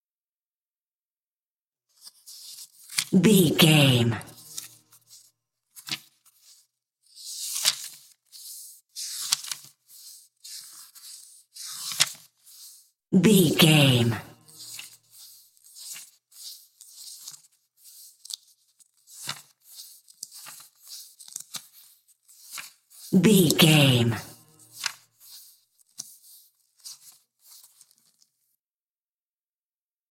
Book pages paper
Sound Effects
foley